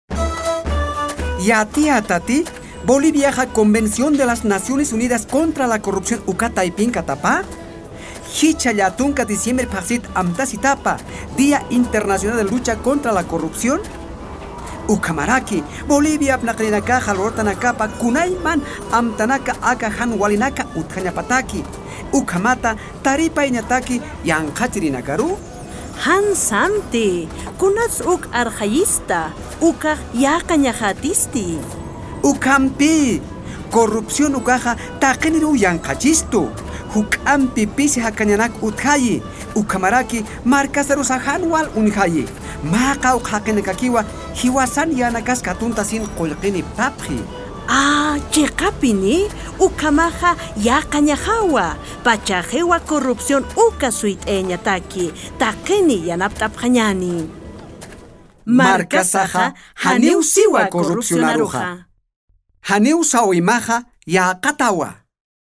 UNODC Bolivia and the Governement produced a radio spot to promote the United Nations Convention against Corruption, the International Anti-Corruption Day and the efforts to fight corruption in Bolivia.